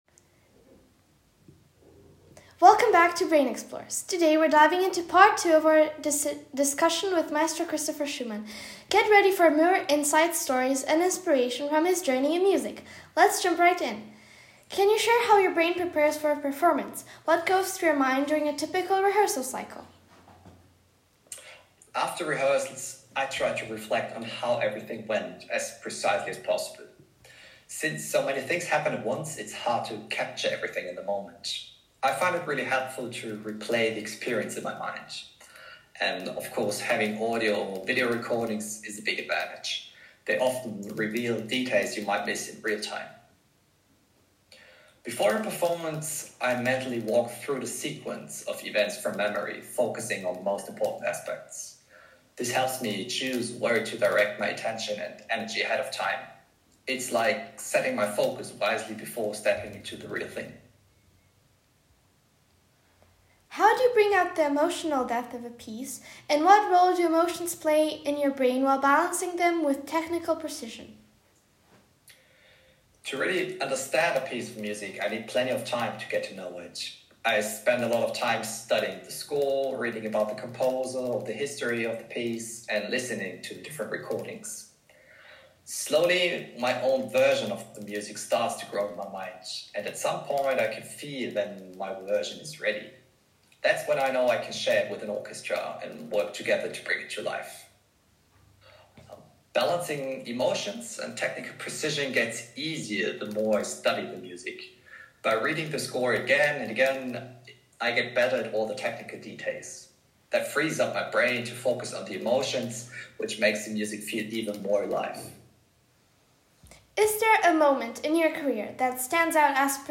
In this episode of Brain Explorers, we sit down with the renowned conductor